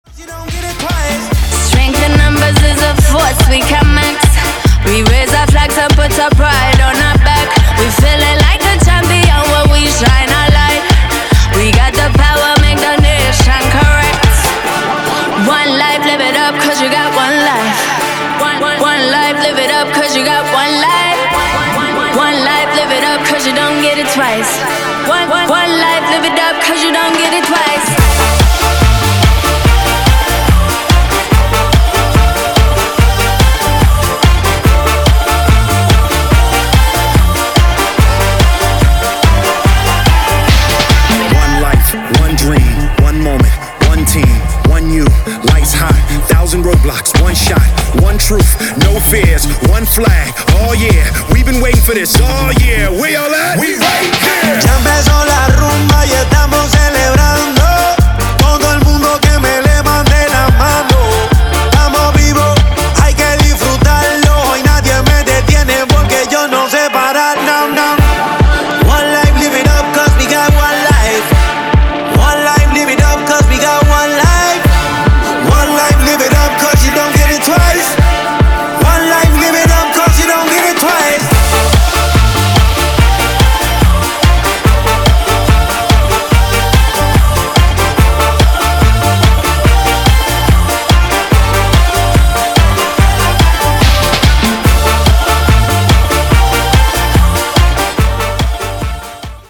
• Качество: 320, Stereo
зажигательные
dance
Moombahton
вдохновляющие
Reggaeton